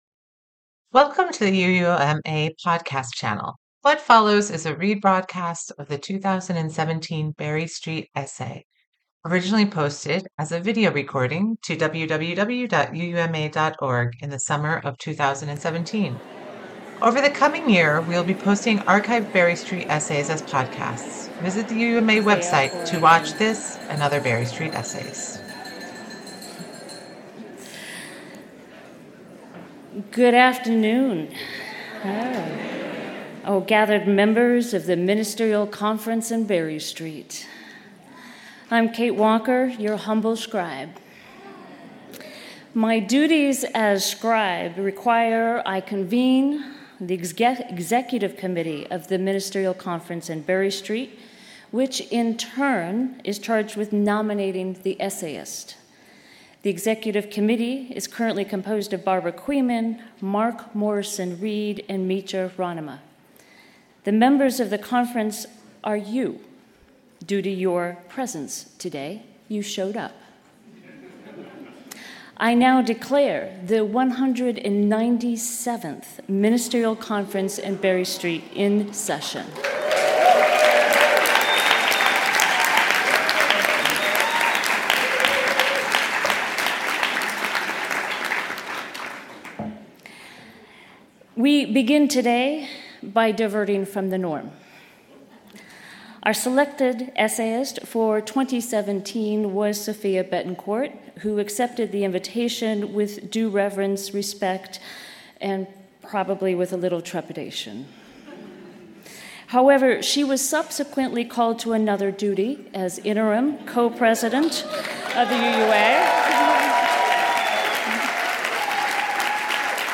197th Berry Street Essay Delivered by Panelists
in New Orleans, LA on June 21, 2017